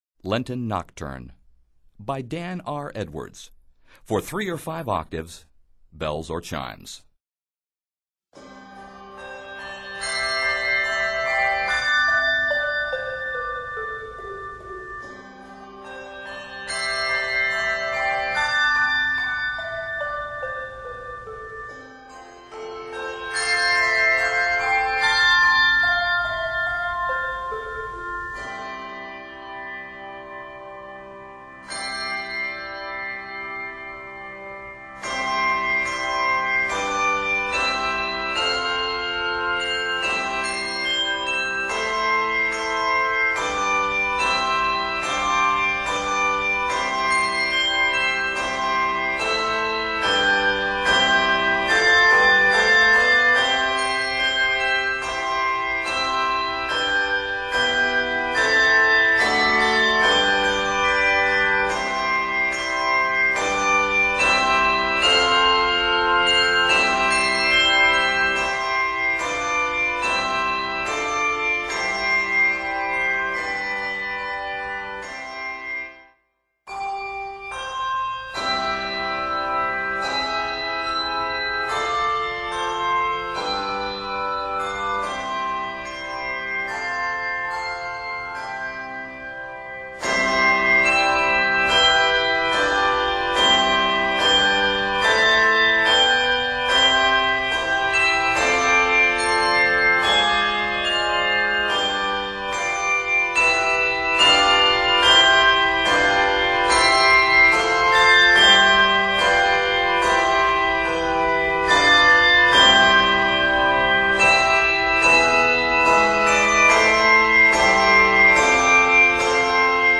Arranged in C Major